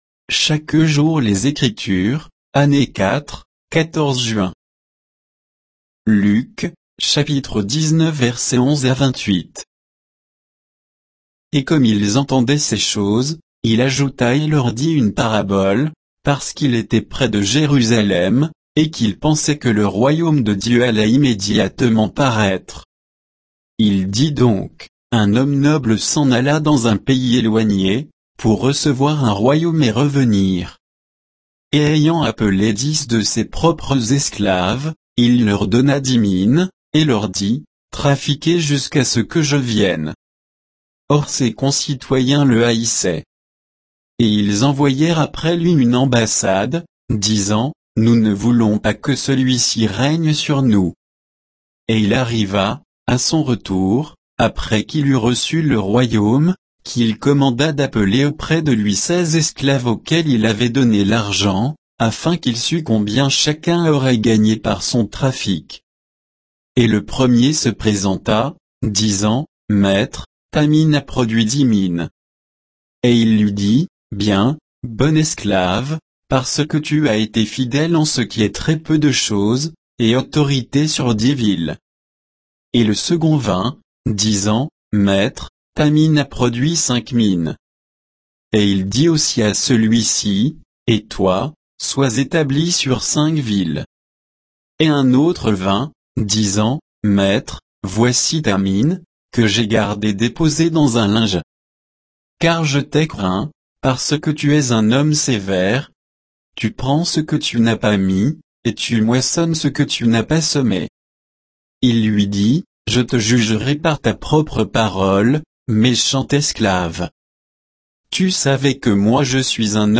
Méditation quoditienne de Chaque jour les Écritures sur Luc 19, 11 à 28